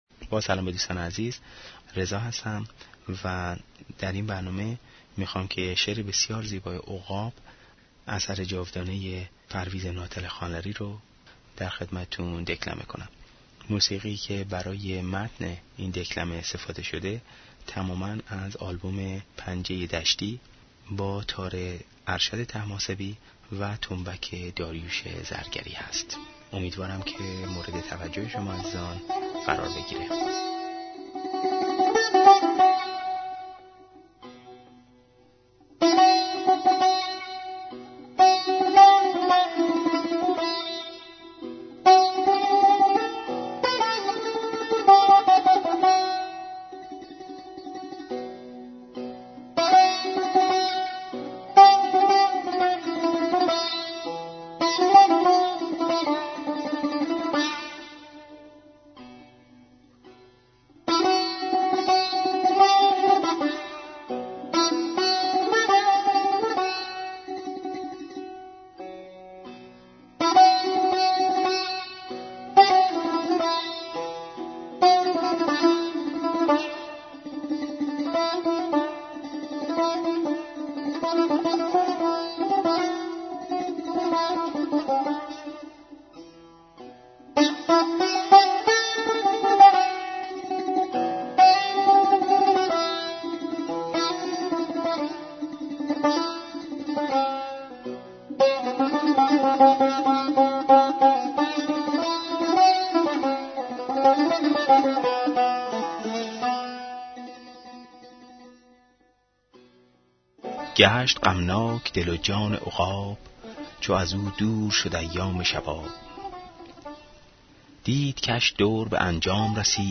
برای شنیدن شعر همراه با موسیقی اینجا را کلیک کنید.